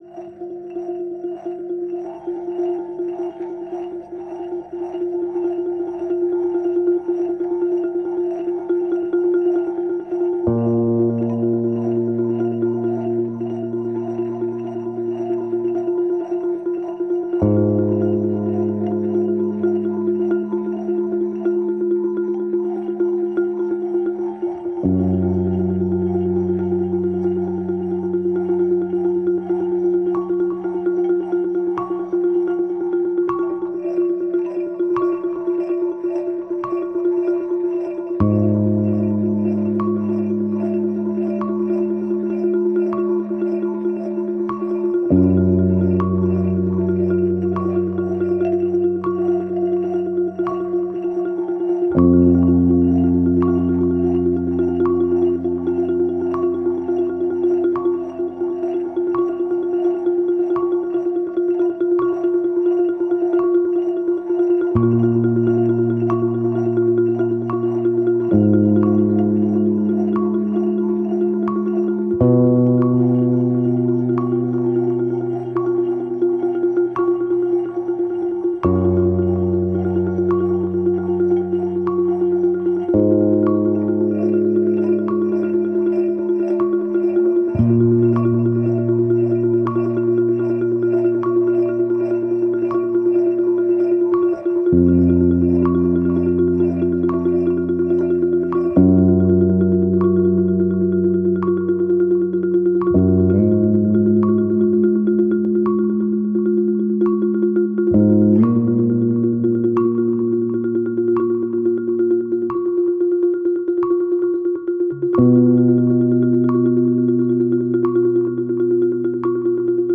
Xylophon und E-Bass